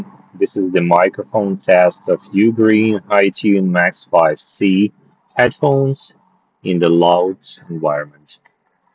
Microphone
The microphone in the UGREEN HiTune Max5c is pretty good, a 7 out of 10, made at a fairly high level both in class and overall.
In noisy conditions:
mic-highnoise-en.mp3